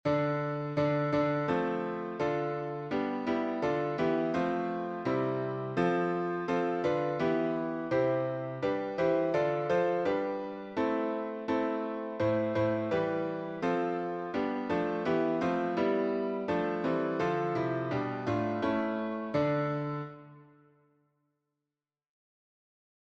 Hungarian carol